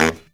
LOHITSAX01-R.wav